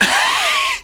HARD ATTAC.wav